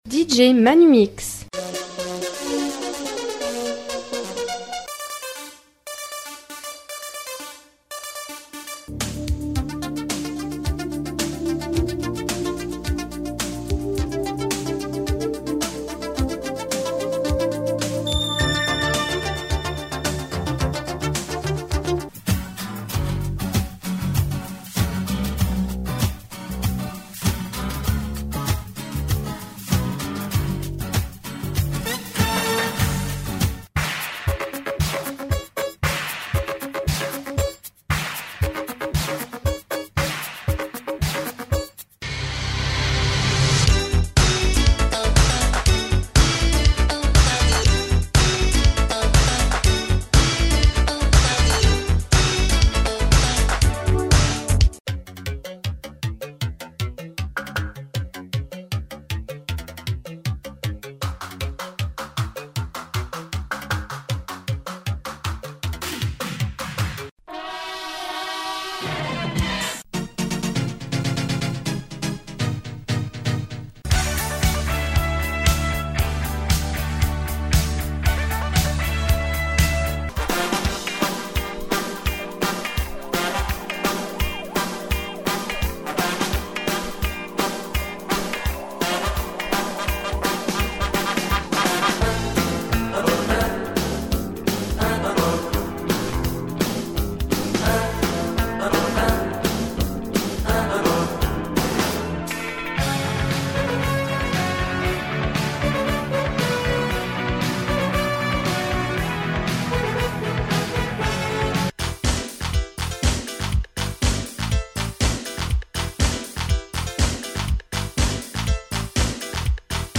c'est un mélange de Maxi 45 tours des années 80.